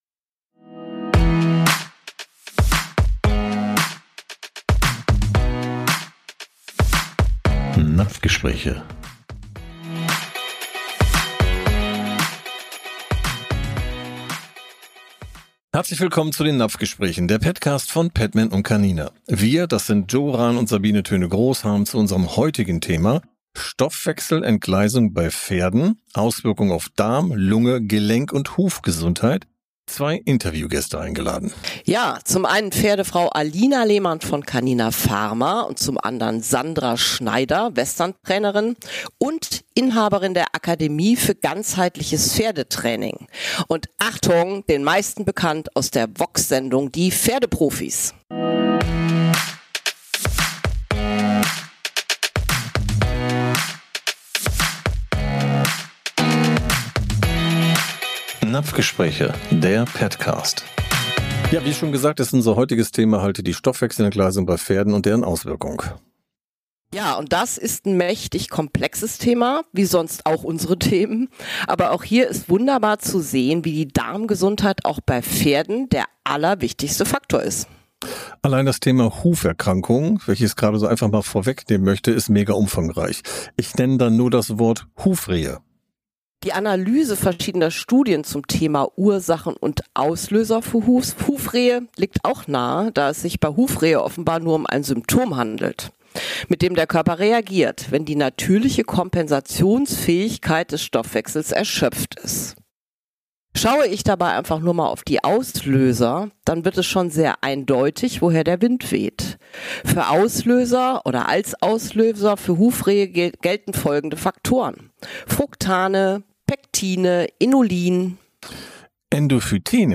Interviewgäste